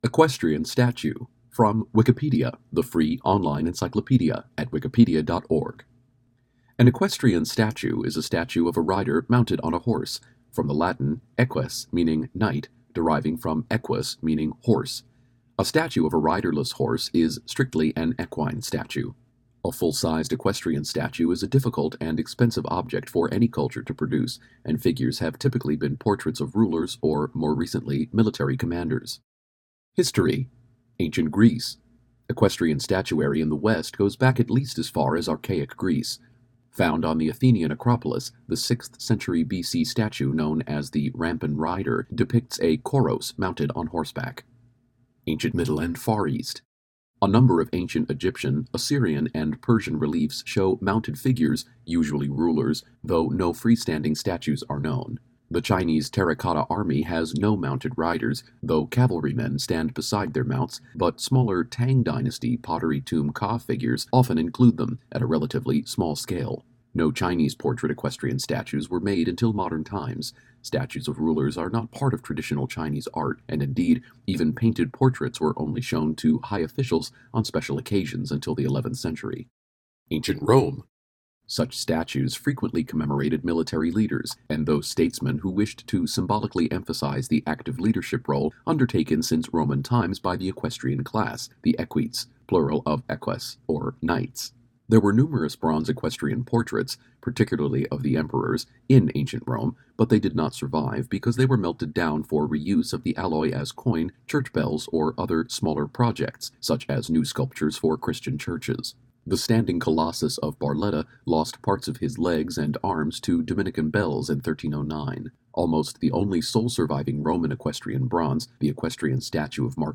English spoken article